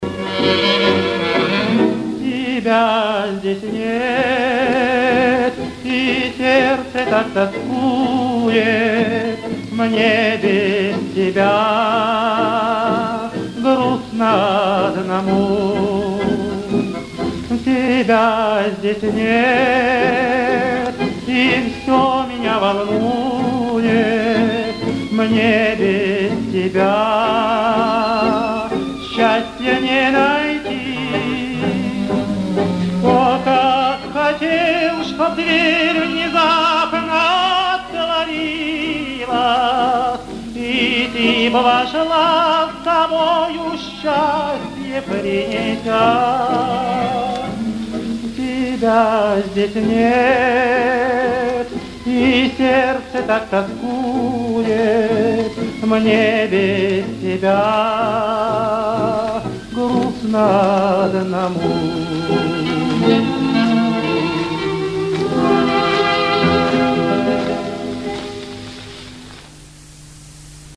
зацени ещё раз моё чувство прекрасного(списано с моего кассетника РОССИЯ М-311 С